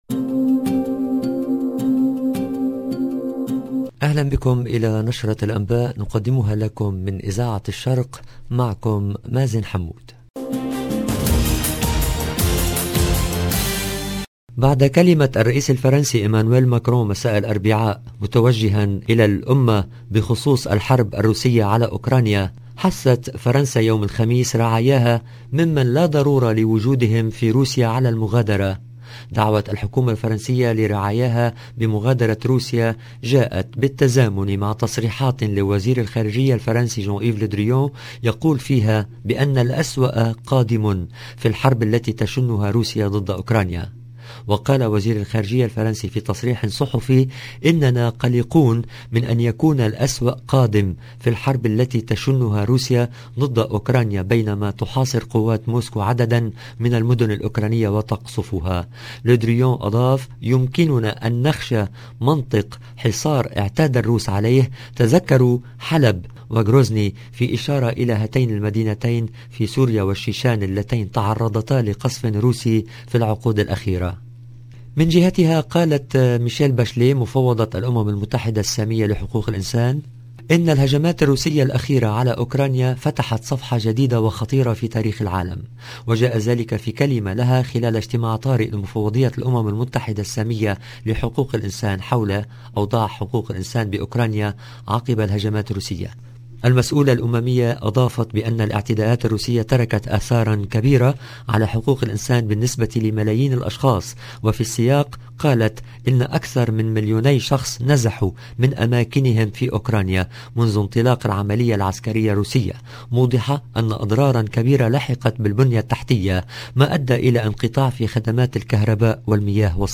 LE JOURNAL DU SOIR EN LANGUE ARABE DU 3/3/2022